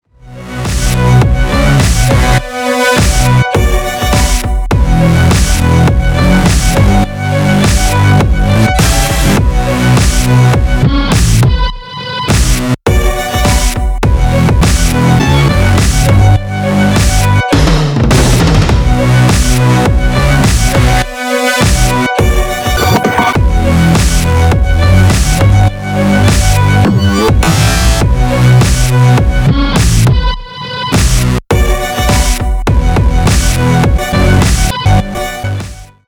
• Качество: 320, Stereo
саундтреки
скрипка
glitch hop